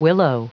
Prononciation du mot willow en anglais (fichier audio)